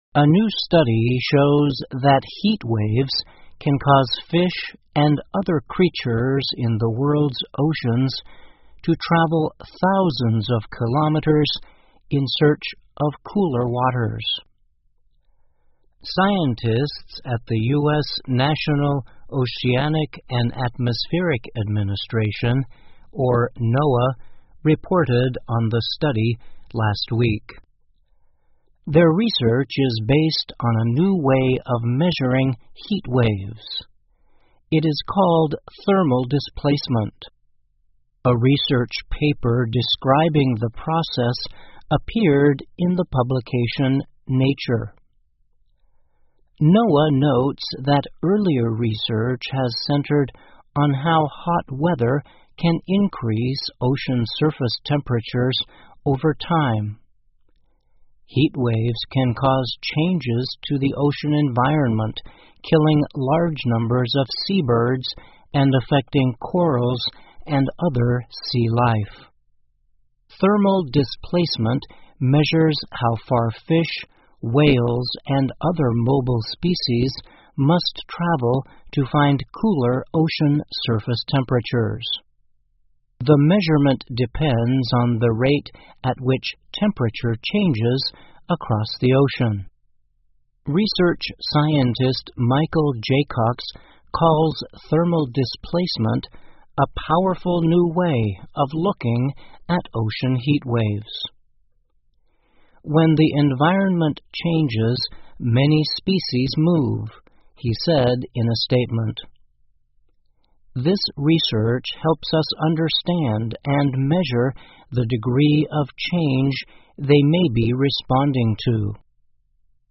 VOA慢速英语--研究称热浪会迫使海洋生物逃离 听力文件下载—在线英语听力室